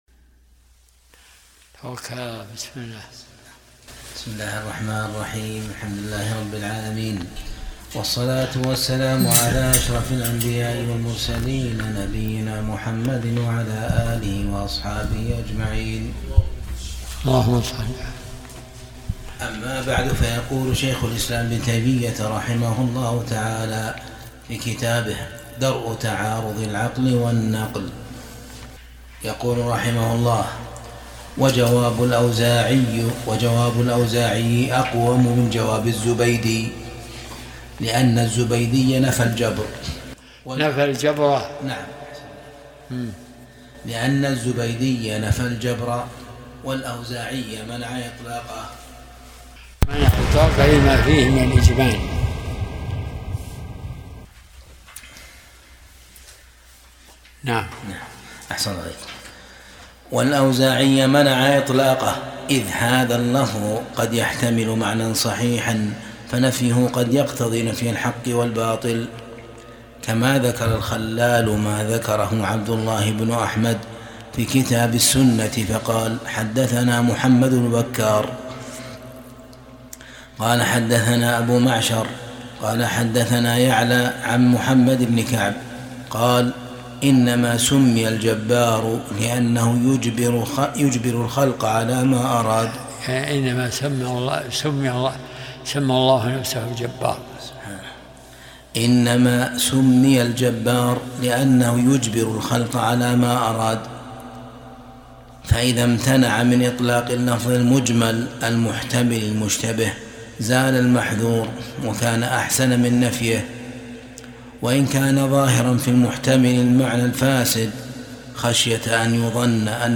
درس الأحد 73